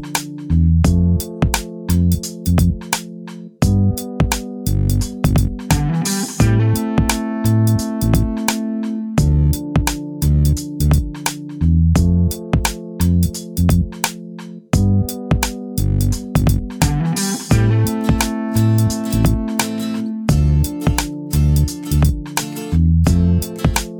Minus Main Guitar Pop (1990s) 3:32 Buy £1.50